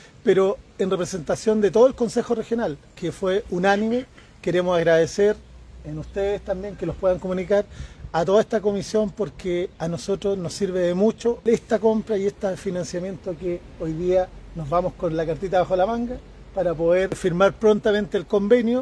Por su parte, el alcalde de la comuna, Cristian Oses, se refirió a los beneficios que estos proyectos de mejoras para la comuna que prontamente serán firmados para ser oficializados.